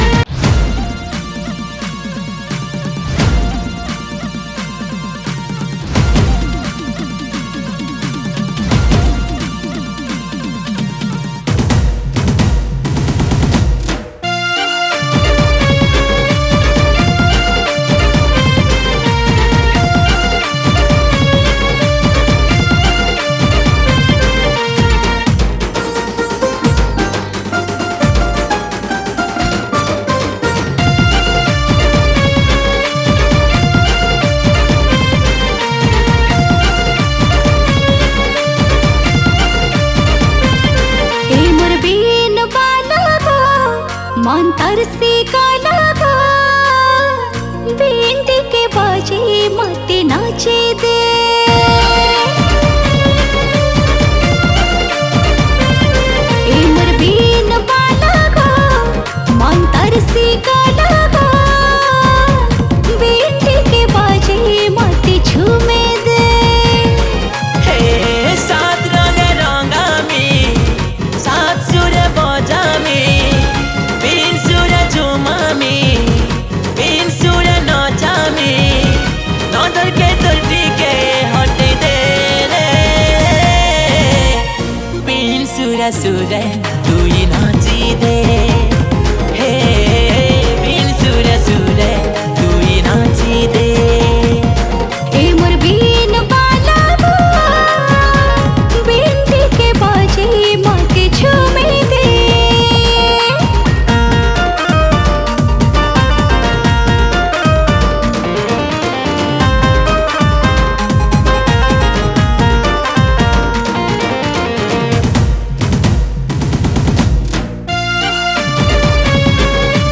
Sambalpuri